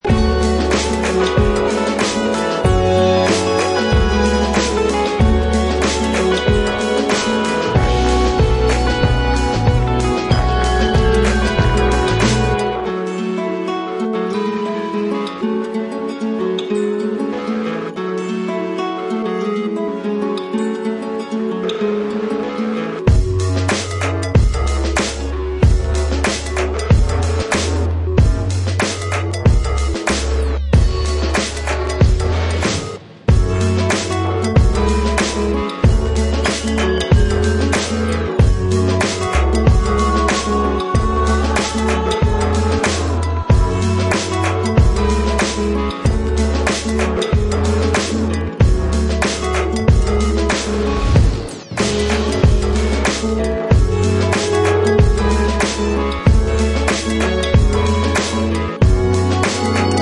Styl: House, Lounge, Breaks/Breakbeat